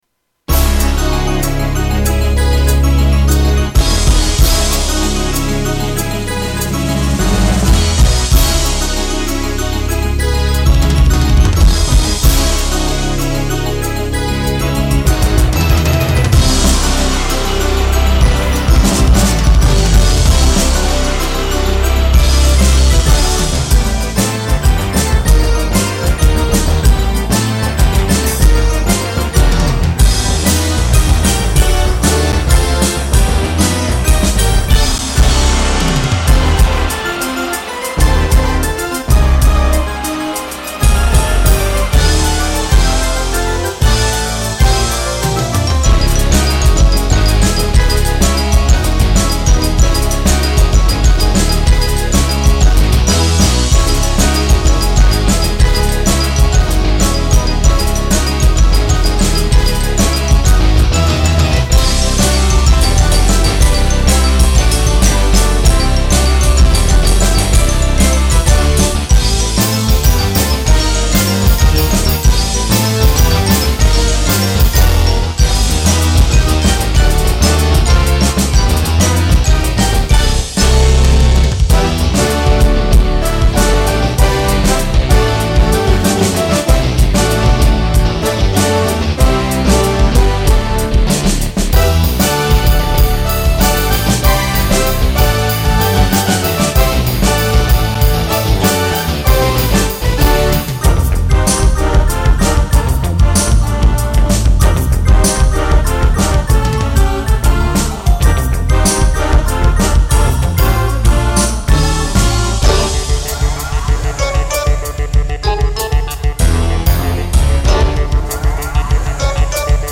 Tags: Kurzweil K2500 Kurzweil K2500 clips Kurzweil K2500 sounds Kurzweil Synthesizer